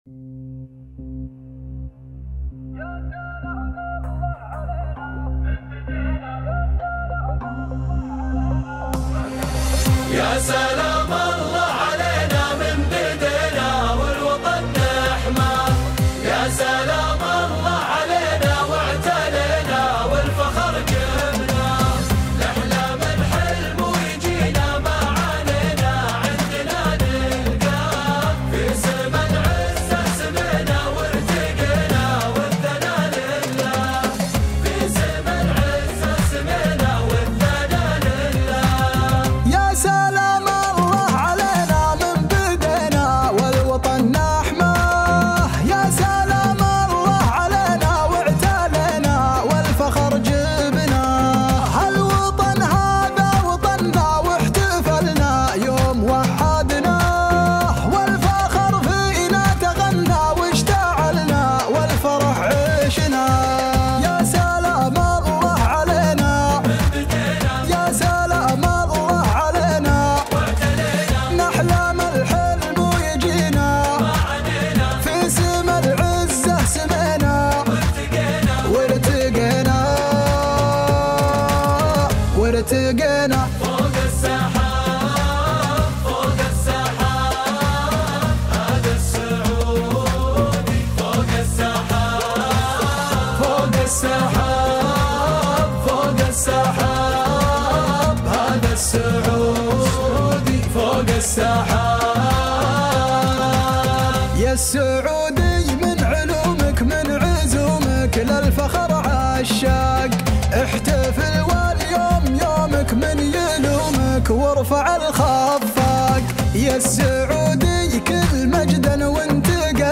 شيلات وطنية